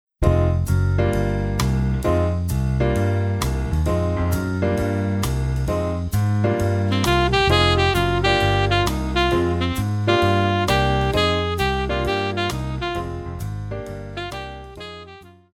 爵士,流行
中音萨克斯风
乐团
演奏曲
轻柔爵士,摇滚
仅伴奏
没有主奏
有节拍器